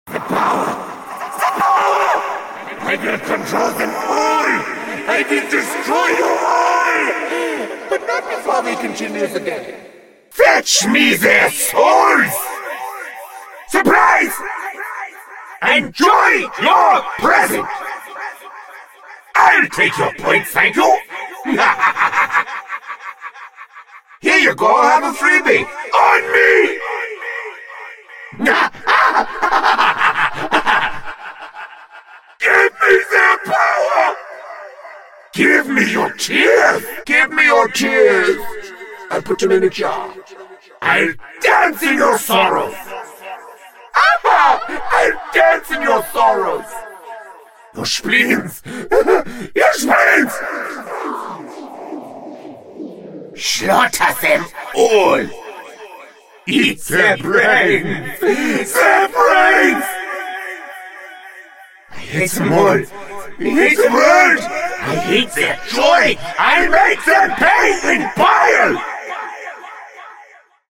Part 2 : Power Ups Sound Effects Free Download